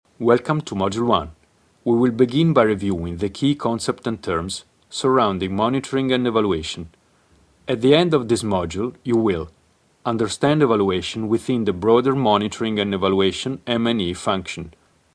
Amichevole, professionale e business-oriented
Sprechprobe: eLearning (Muttersprache):